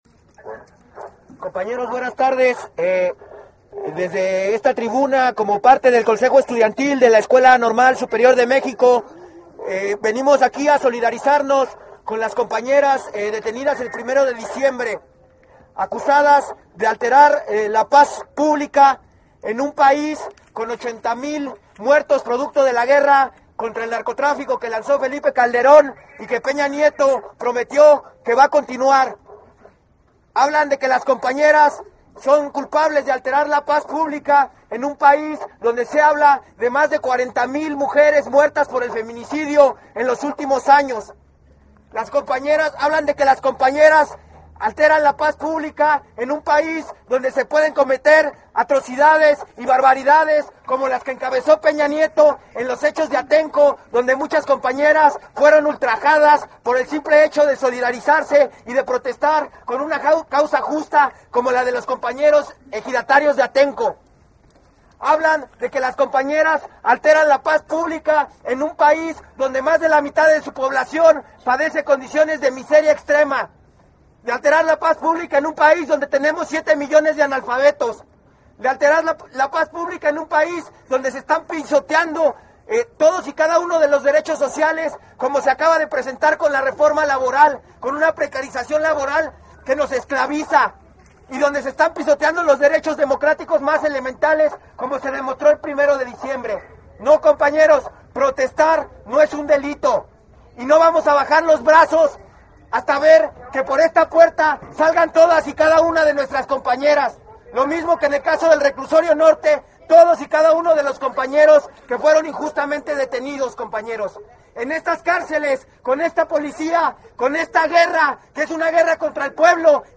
Concentración en el Penal de Santa Martha Acatitla por la liberación de las 11 presas políticas